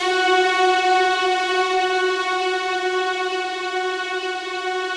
BIGORK.F#3-L.wav